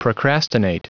Prononciation du mot procrastinate en anglais (fichier audio)
Prononciation du mot : procrastinate